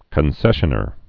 (kən-sĕshə-nər)